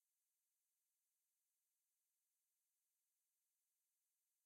blank.ogg